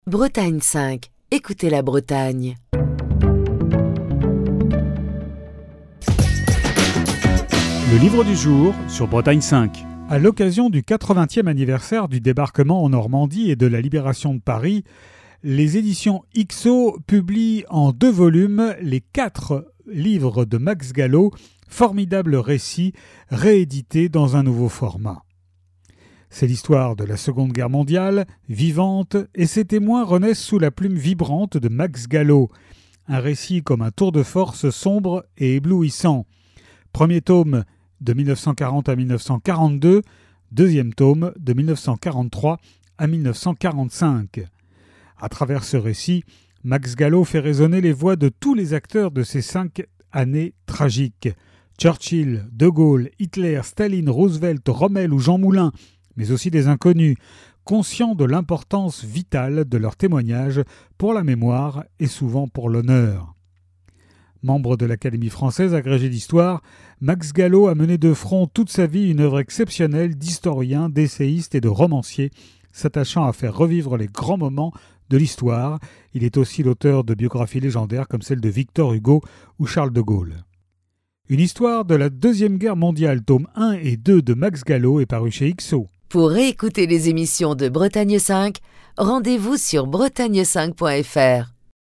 Chronique du 24 mai 2024.